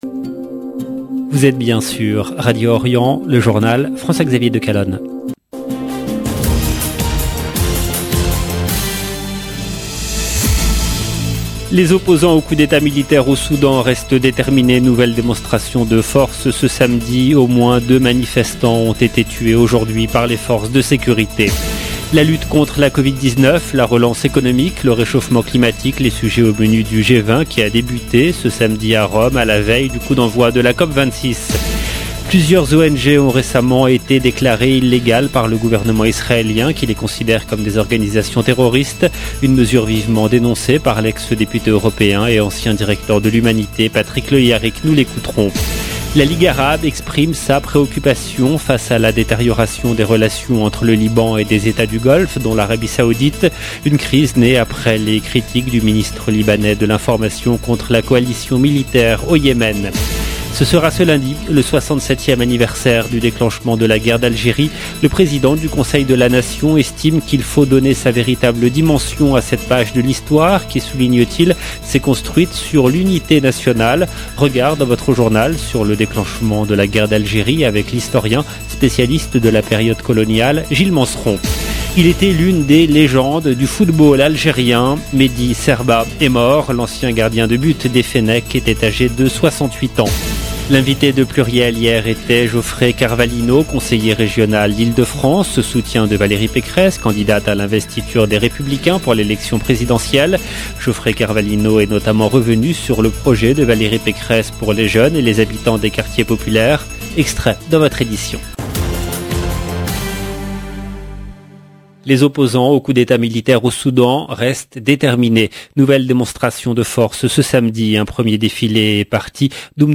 EDITION DU JOURNAL DU SOIR EN LANGUE FRANCAISE DU 30/10/2021